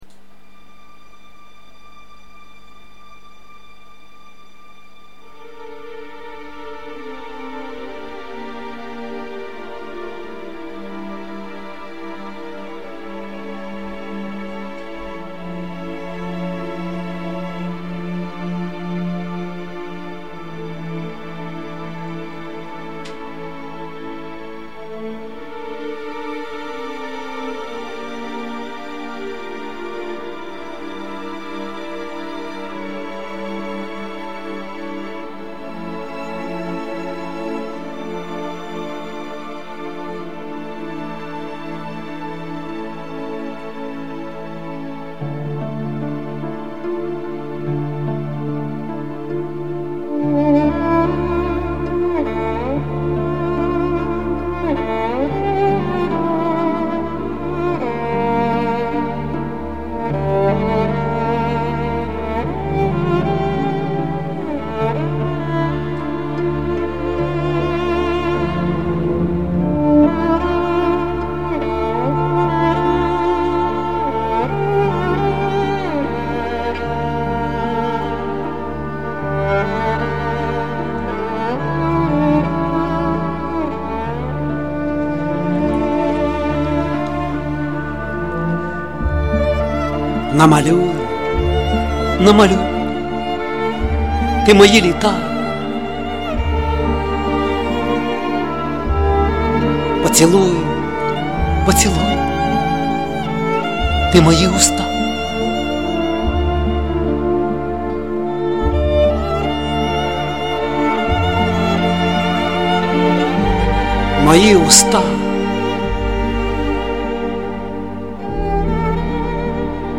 Пречудова музика!
Як на мене то може треба щоб голос звучав більш ніжно і плавно.